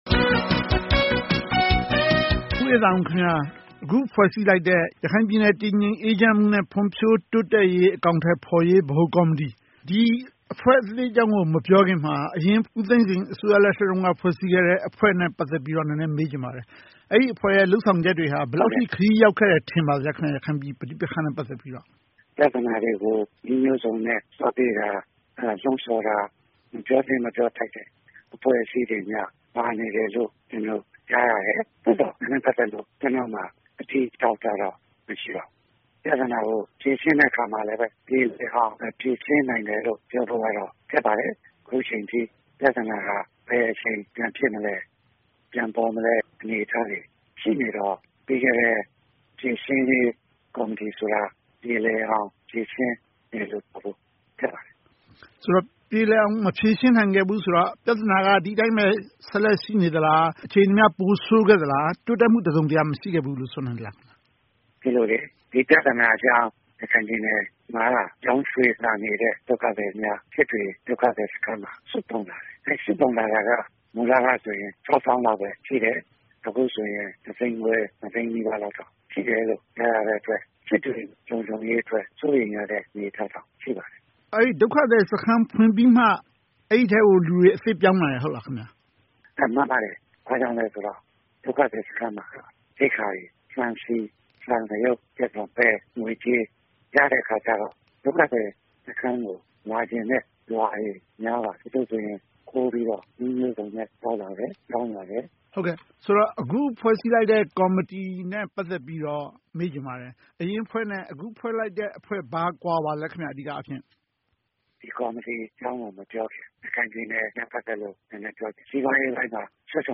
ဆက်သွယ်မေးမြန်း ဆွေးနွေးသုံးသပ်ထားပါတယ်။